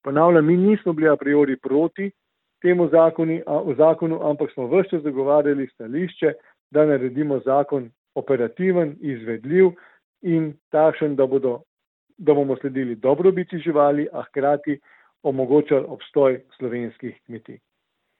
Jože Podgoršek za Koroški radio:
izjava Podgorsek za splet.mp3